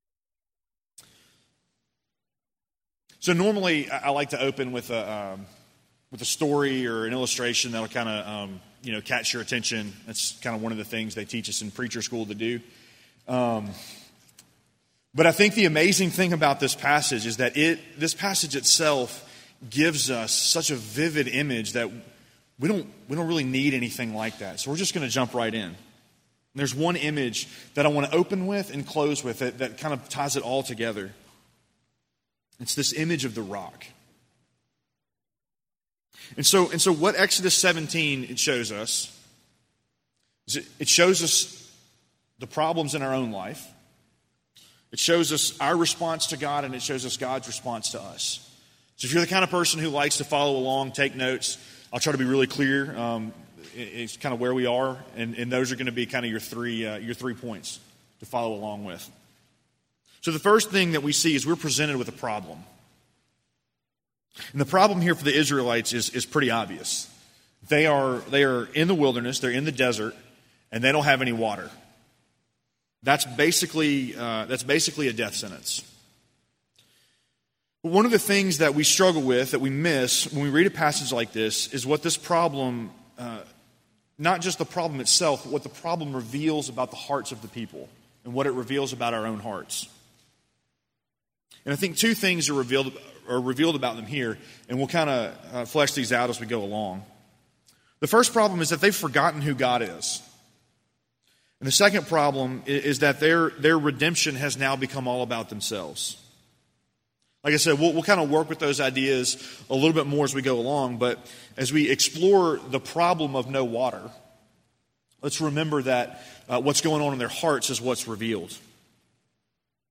Sermon on Exodus 17:1-7 from September 2, 2018